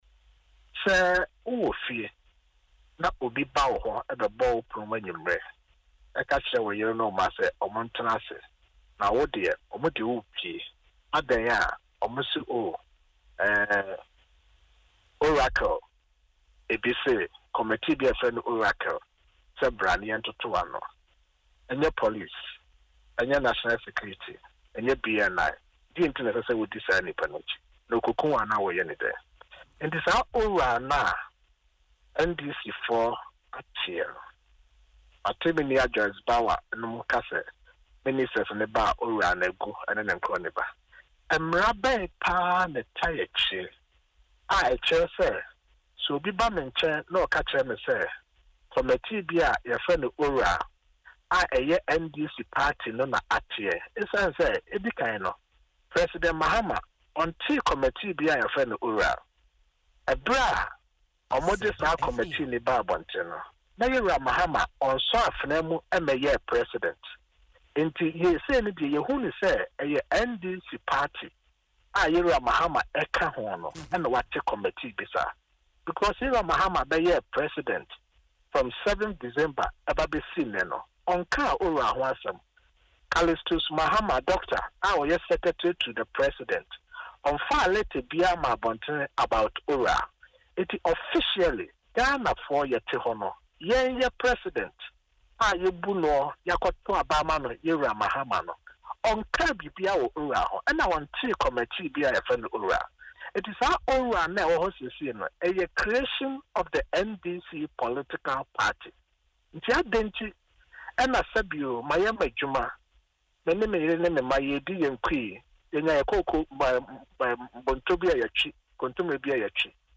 In an interview on Asempa FM’s Ekosii Sen, he reiterated the New Patriotic Party (NPP) members’ concerns about the legal framework of the committee.